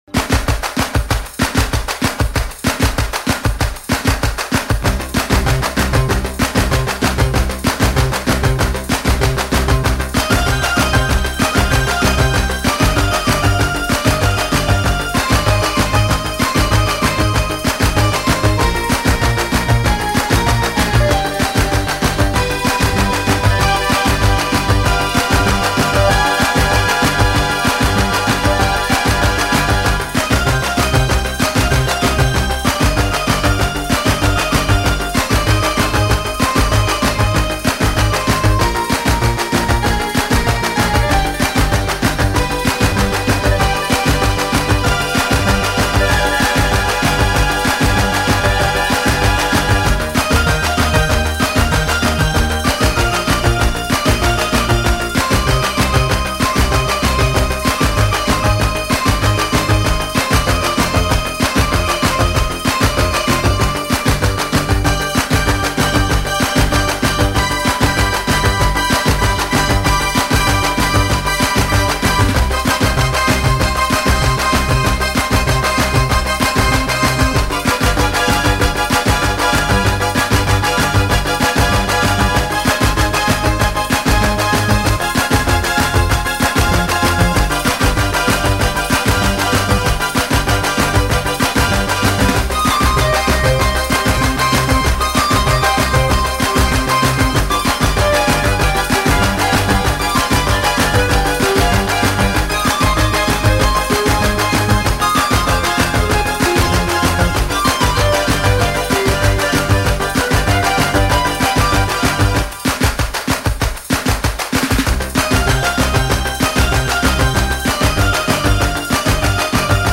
ТОЧНЫЙ ЖАНР(Ы): Ambient, New Age, Electronic